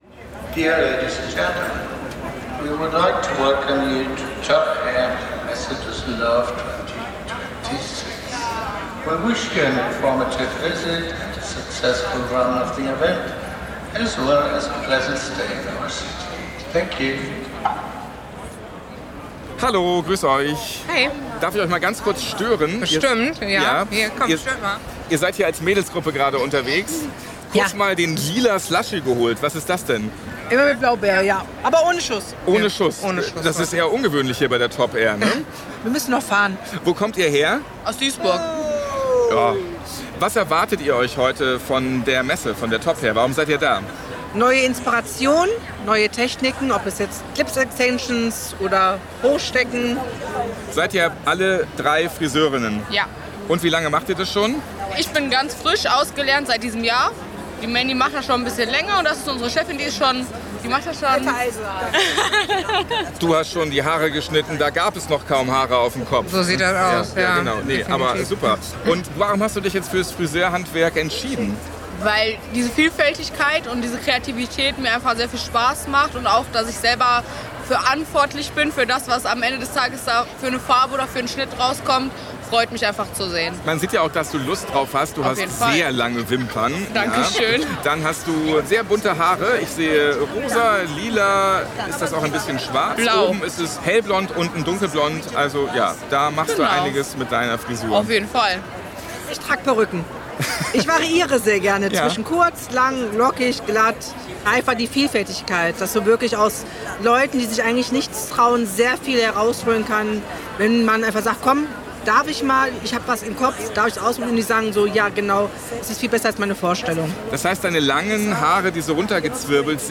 Teil 2 von der Top Hair in Düsseldorf: